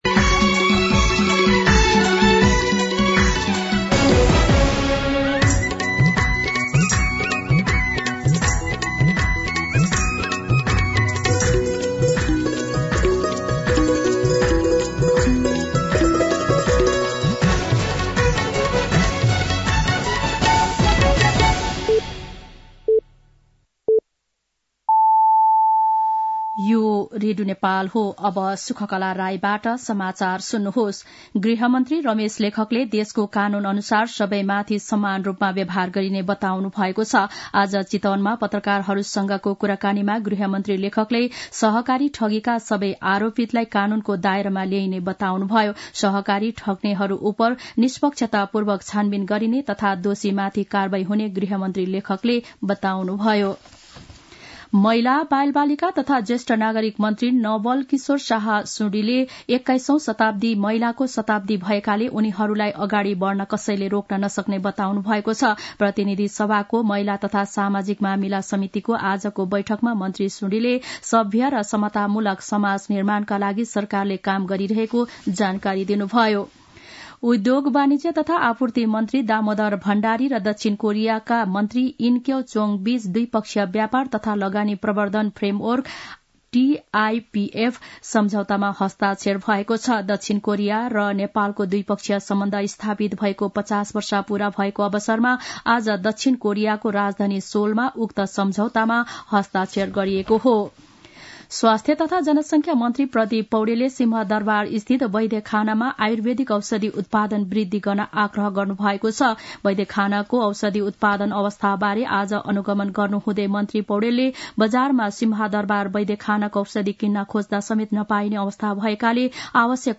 दिउँसो ४ बजेको नेपाली समाचार : ५ पुष , २०८१